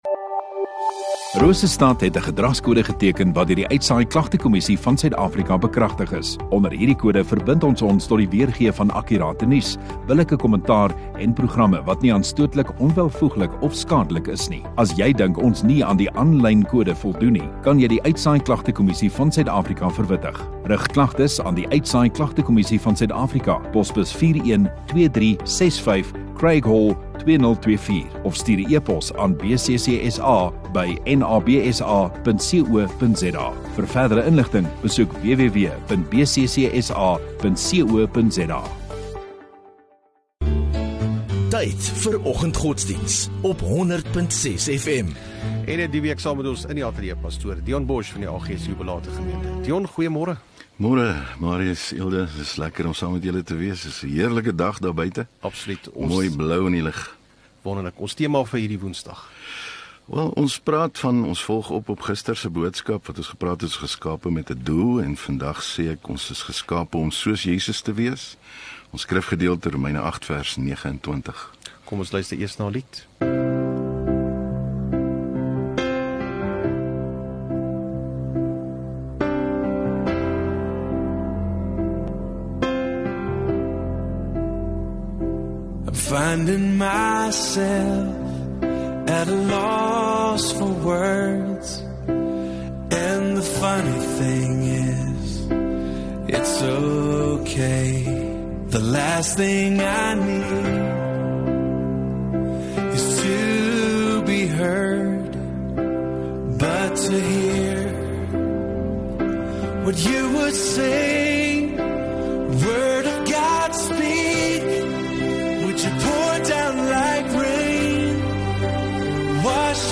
23 Oct Woensdag Oggenddiens